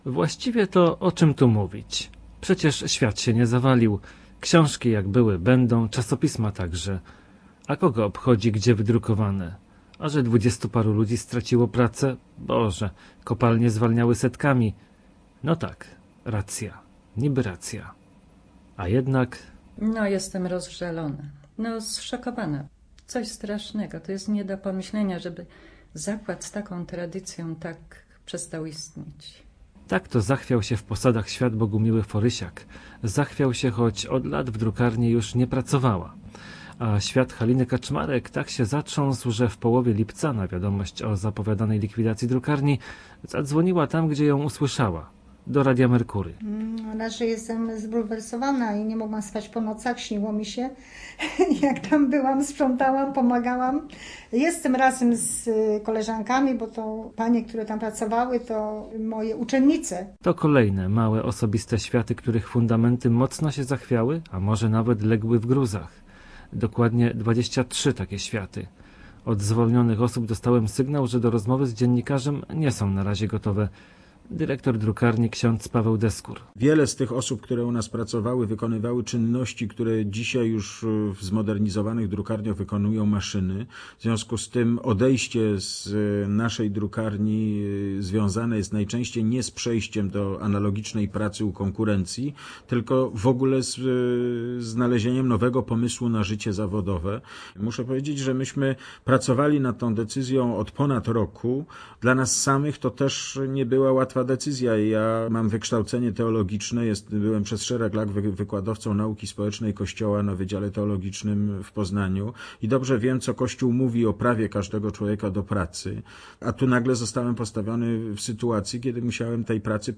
75oi8kuk8ldmt3u_reportaz_ksiegarnia_swwojciecha.mp3